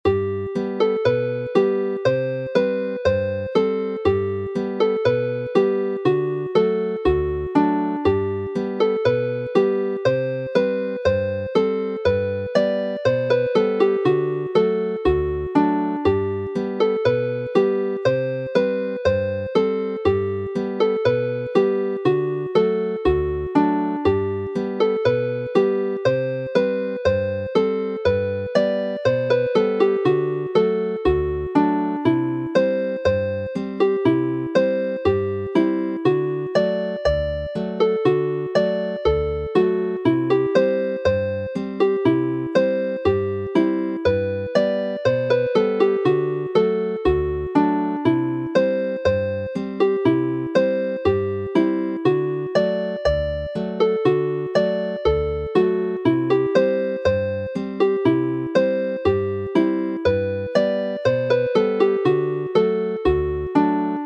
Chwarae'n araf
Play slowly